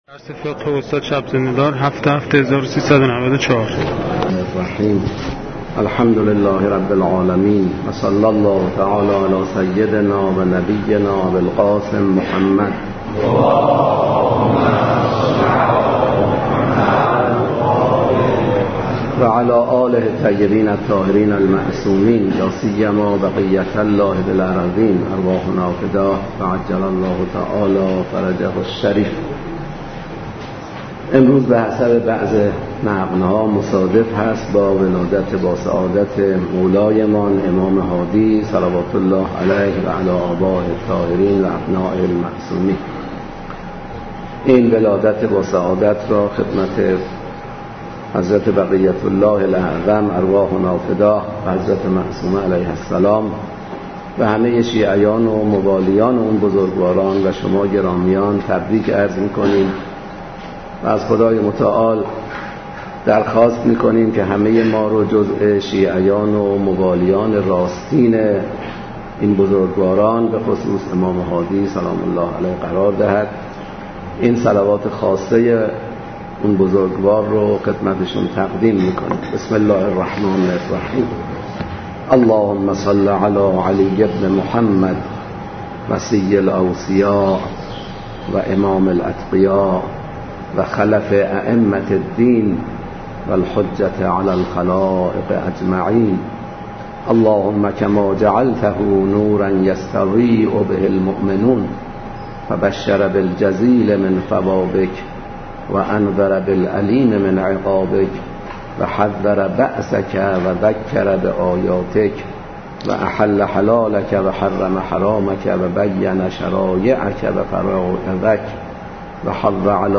لازم به ذکر است: متن ذیل پیاده شده از صوت درس می‌باشد و هیچگونه ویرایشی روی آن اعمال نشده است.